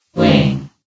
New & Fixed AI VOX Sound Files